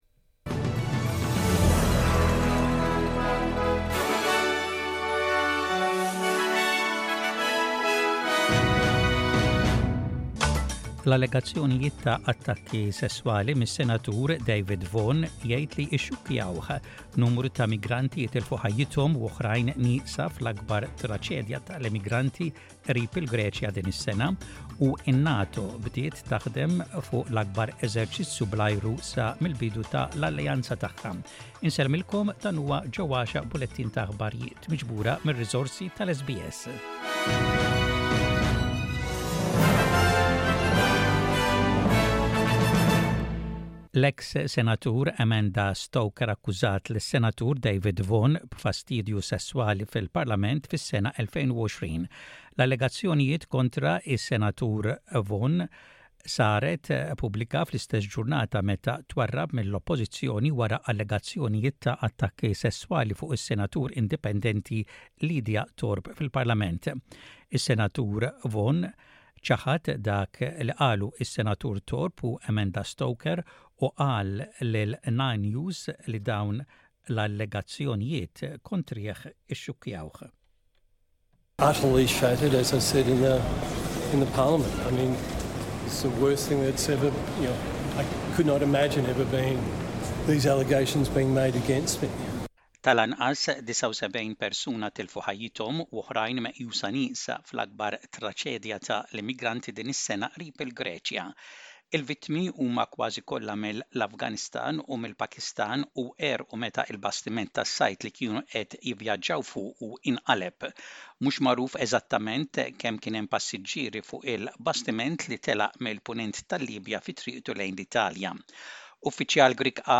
SBS Radio | Maltese News: 16/06/23